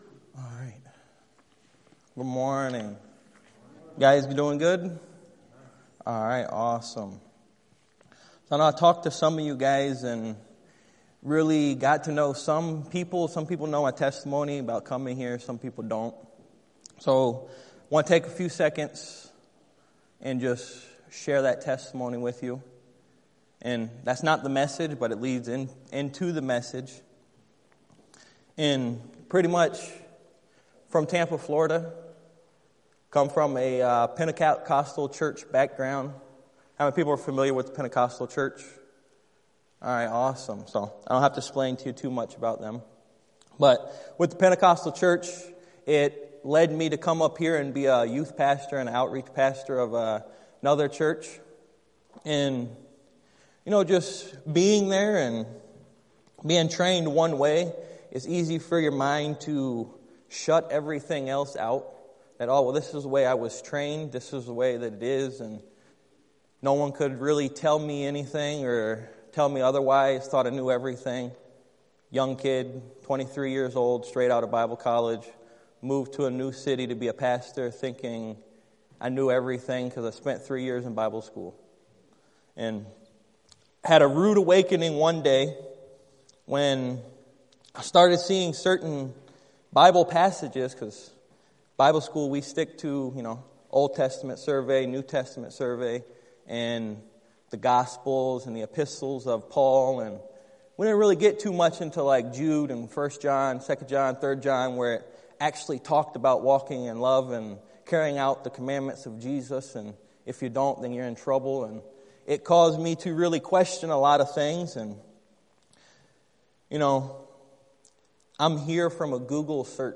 2 Samuel 11:1-12:13 Service Type: Sunday Morning Download Files Notes « What If Jesus Meant Everything He Said?